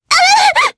Selene-Vox_Damage_jp_03.wav